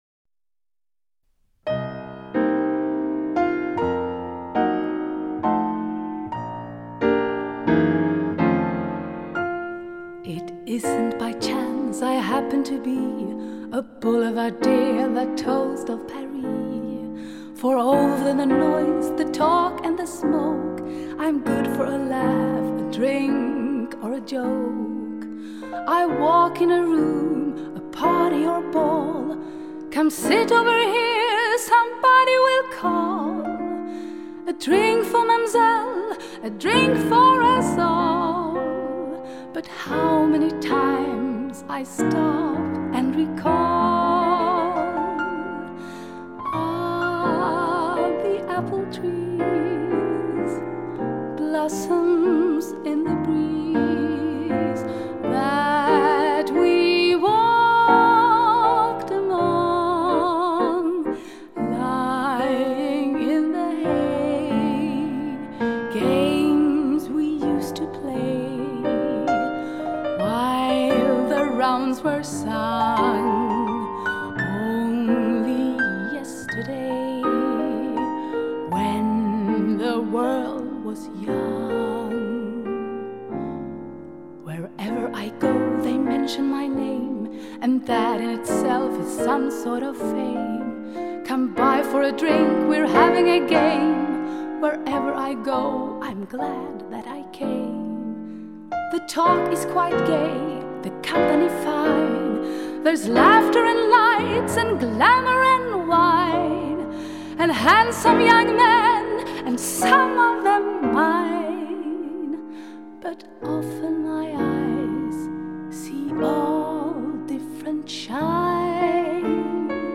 Jazz/visa/blues Goda referenser finns att tillgå.